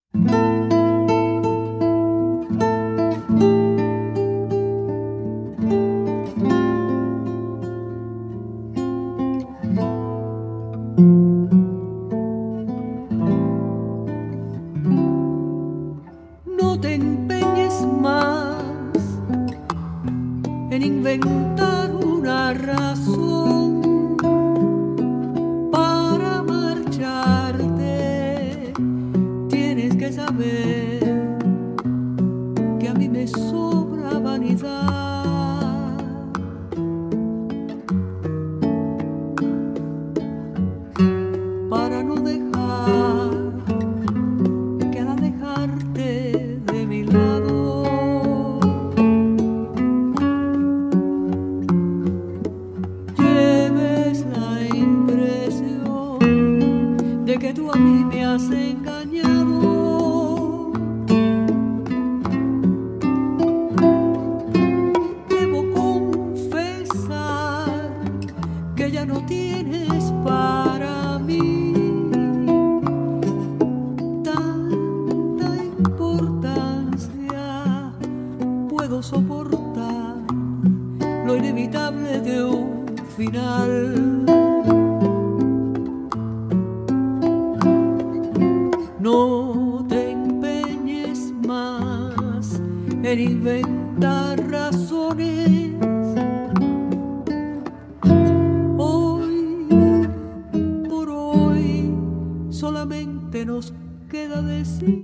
最高！弾き語りによる”フィーリン”！